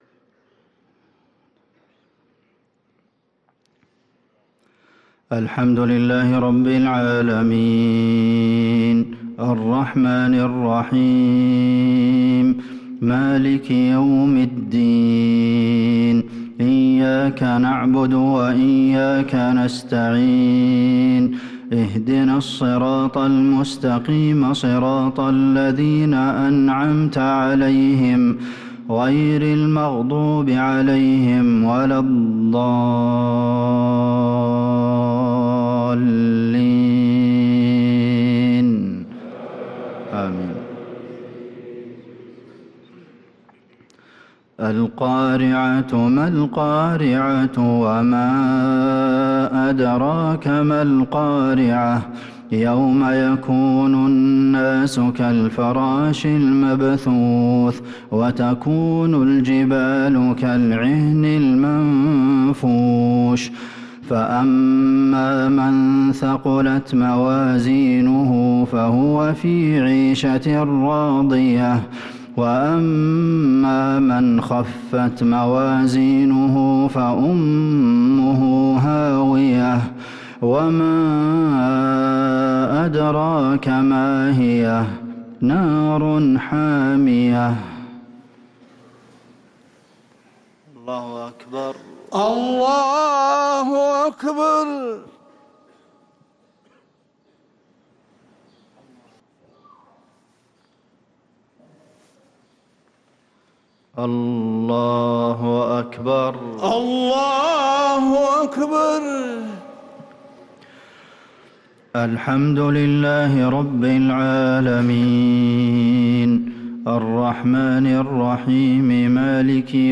صلاة المغرب للقارئ عبدالمحسن القاسم 11 شعبان 1445 هـ
تِلَاوَات الْحَرَمَيْن .